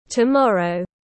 Ngày mai tiếng anh gọi là tomorrow, phiên âm tiếng anh đọc là /təˈmɒr.əʊ/
Tomorrow /təˈmɒr.əʊ/